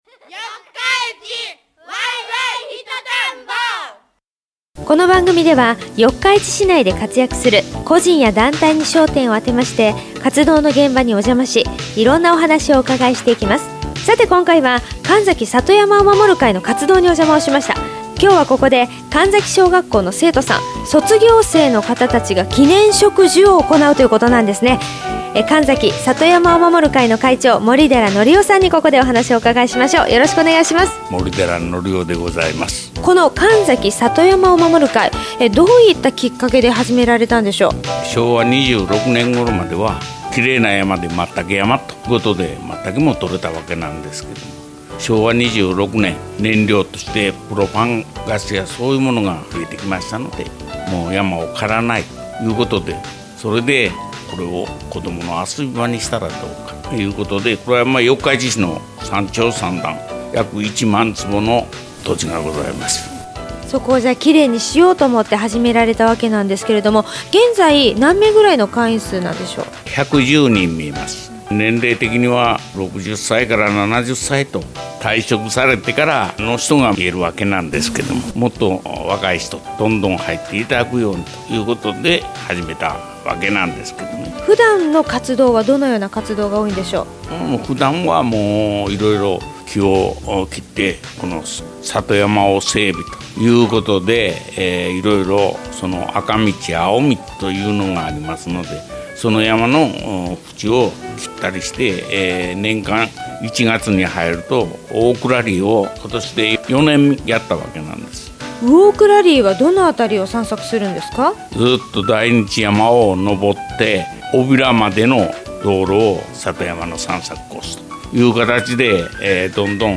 …四日市で生き生きと活動している人の活動現場の声をお届けします。